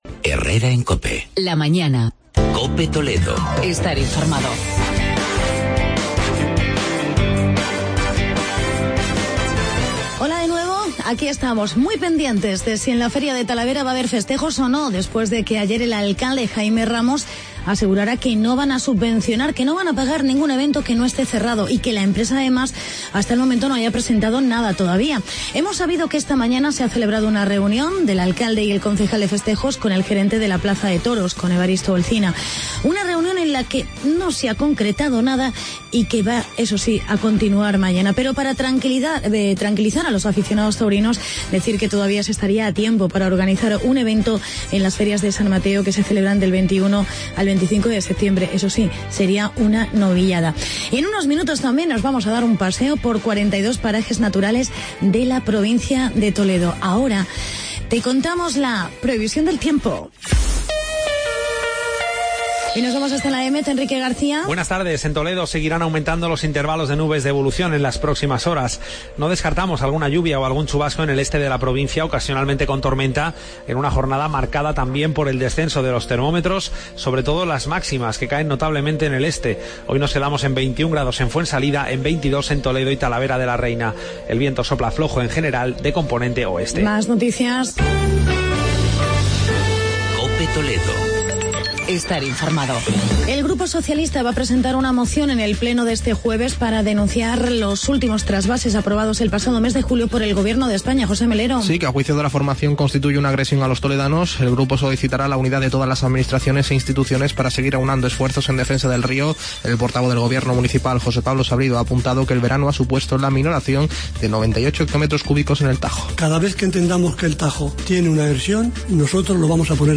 Actualidad y entrevista con el diputado José Luis Fernández, sobre los "42 Parajes Naturales".